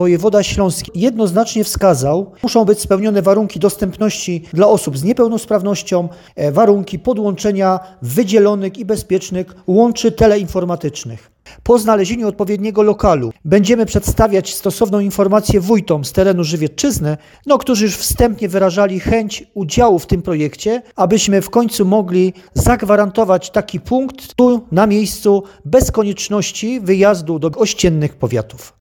Nie odrzucamy pomysłu utworzenia punktu w Żywcu, jednak wymaga to konkretnych inwestycji i środków na utrzymanie lokalu, który musi mieć powierzchnię co najmniej 60 m kw., mówi wicestarosta żywiecki Stanisław Kucharczyk.